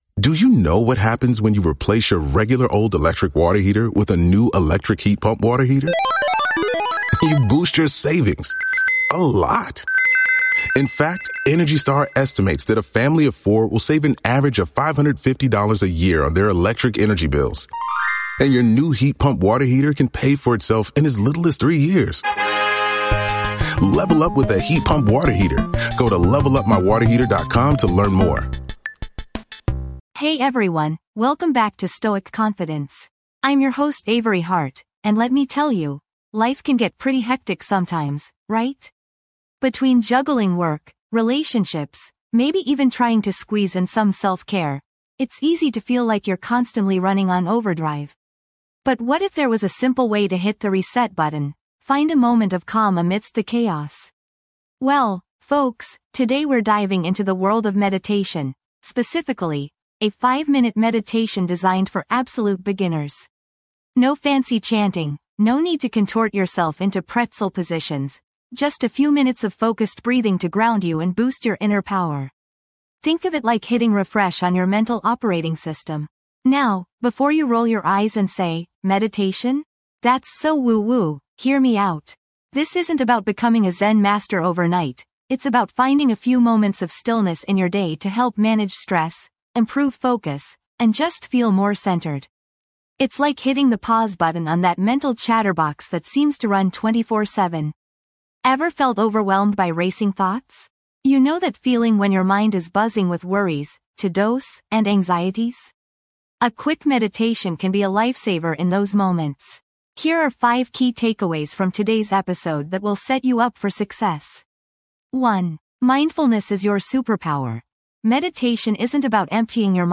Key Takeaways: Learn a beginner-friendly meditation technique, understand the benefits of meditation, explore tips for consistent practice, and experience a 5-minute guided meditation session.
This podcast is created with the help of advanced AI to deliver thoughtful affirmations and positive messages just for you.